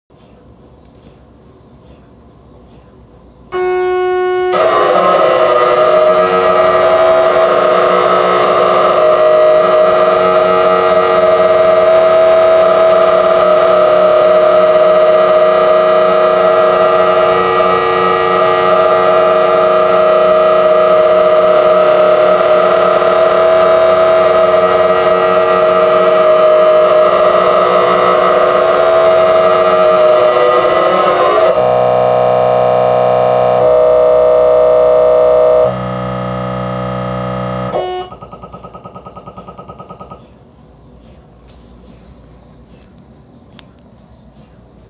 The Most Annoying MRI Scan
This leads to an extremely dissonant set of frequencies when the scanner is running
3D radial UTE sequence with golden angle ordering